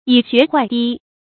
蟻穴壞堤 注音： ㄧˇ ㄒㄩㄝˋ ㄏㄨㄞˋ ㄉㄧ 讀音讀法： 意思解釋： 比喻小事不注意，就會出大亂子。